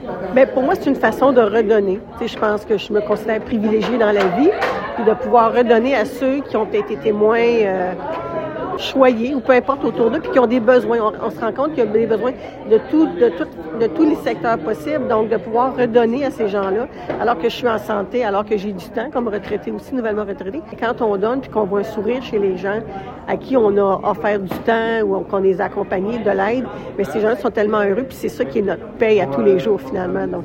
Les festivités de la 52e édition de la Semaine de l’action bénévole ont été lancées plus tôt mardi, lors d’une conférence de presse tenue à l’hôtel de ville.